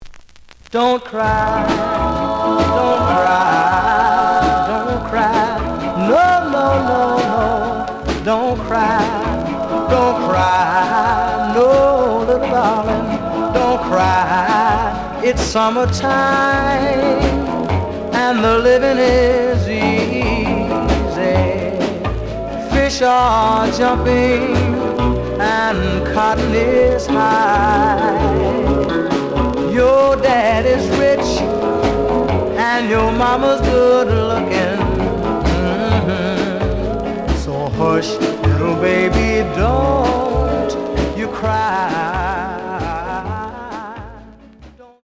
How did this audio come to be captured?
The audio sample is recorded from the actual item. Slight noise on both sides.)